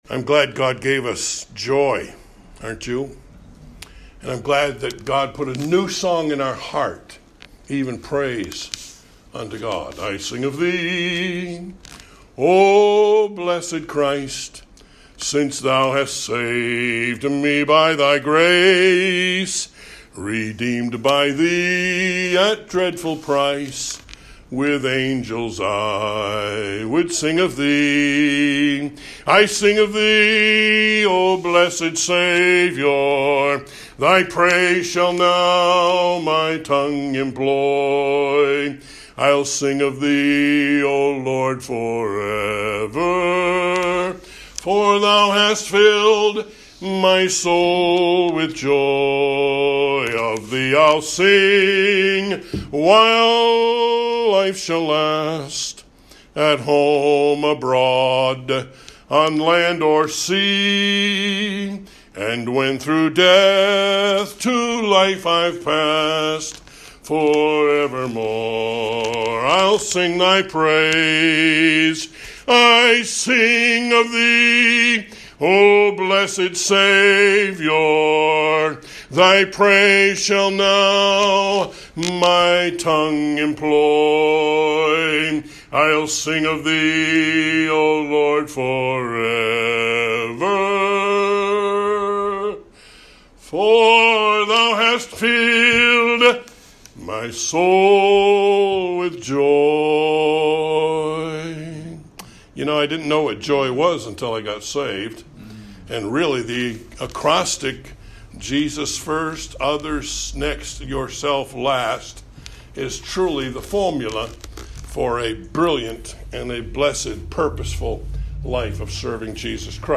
Evangelistic Sermon – Evening 3